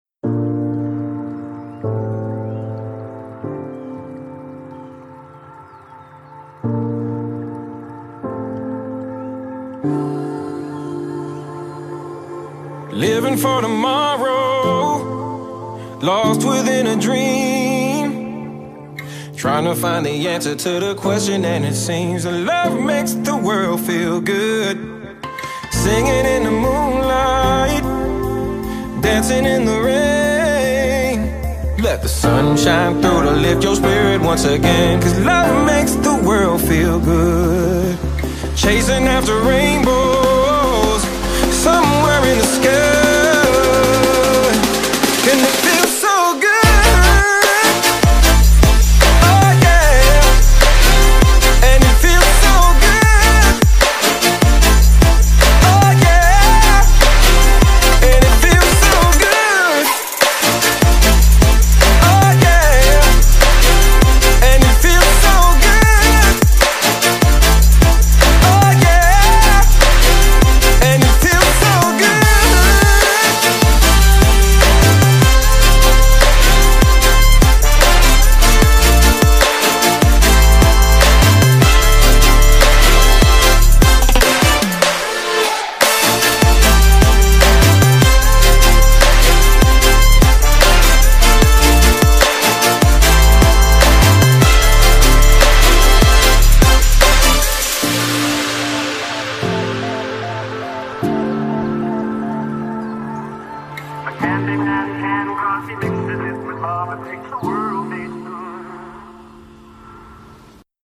BPM75-150
Audio QualityPerfect (High Quality)